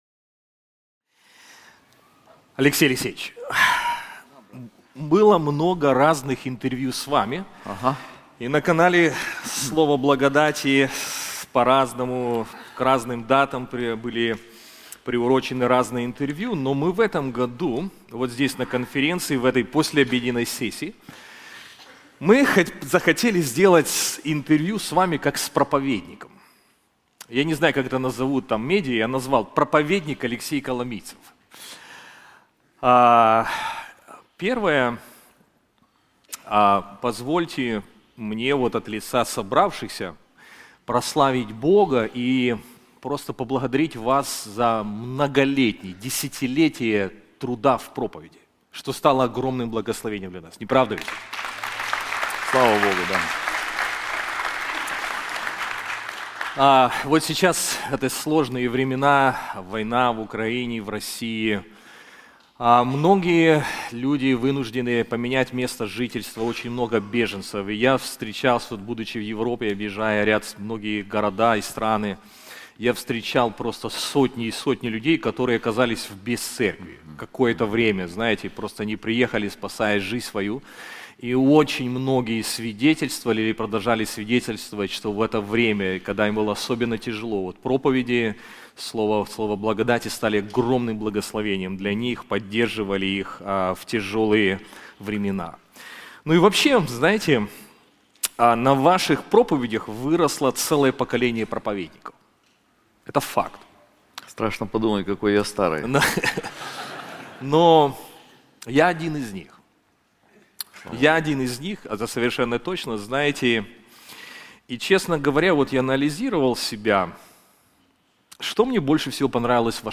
Владыка истории Интервью